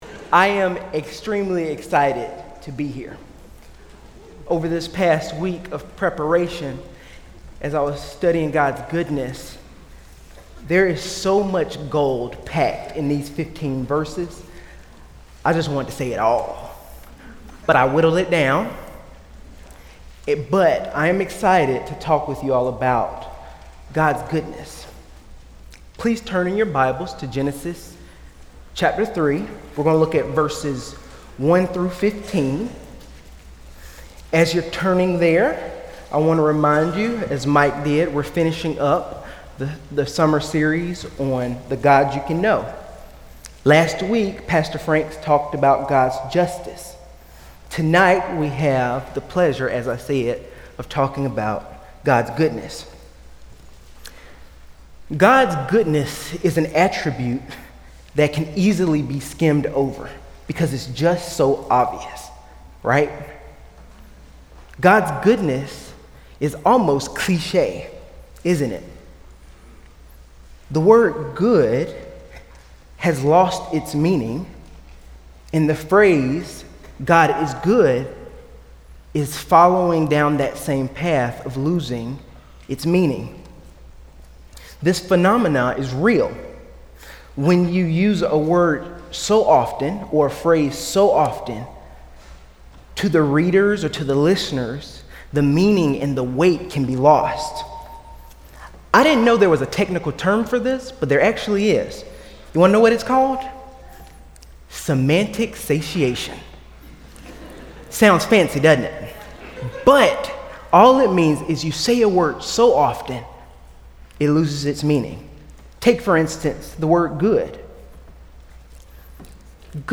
A sermon from the series "Standalone Sermons."